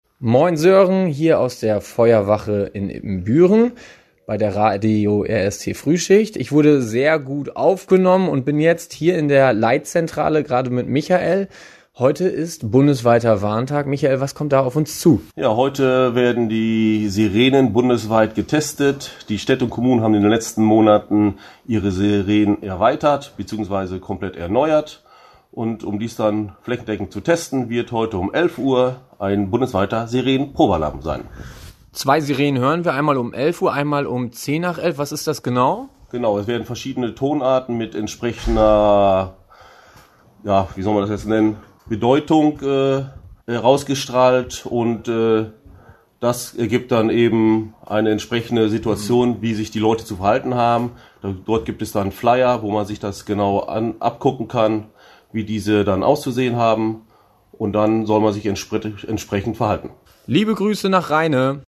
In unserer RADIO RST-Frühschicht treffen wir dich da, wo du morgens arbeitest und sprechen mit dir über aktuelle Themen.
Bundesweiter Warntag